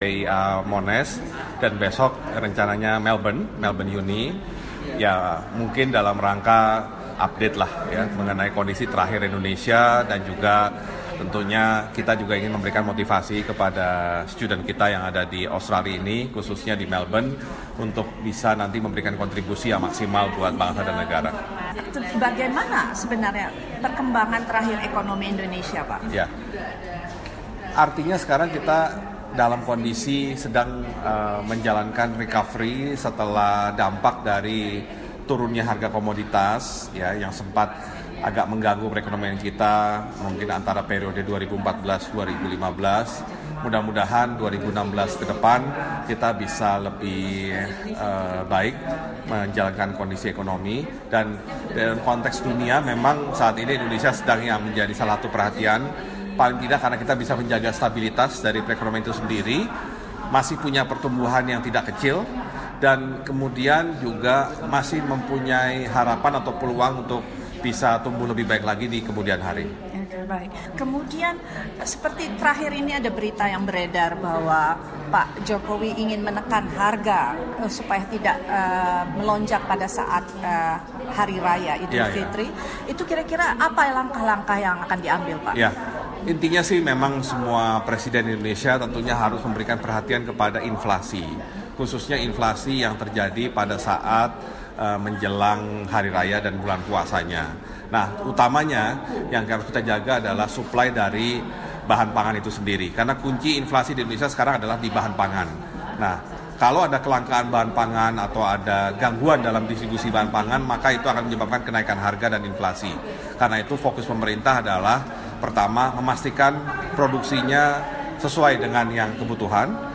Bagaimana pemerintah menjaga stabilitas harga menjelang hari raya? Bapak Bambang Soemantri Brodjonegoro, Menteri Keuangan menjelaskan secara rinci, langkah-langkah yang diambil untuk mencapai stabilitas harga.
Menteri Keuangan Republik Indonesia, Bapak Bambang Soemantri Brodjonegoro menghadiri acara Australian Indonesia Business Forum di Universitas Monash. Bapak Menteri menjelaskan beberapa langkah pemerintah dalam menciptakan ekonomi yang sehat.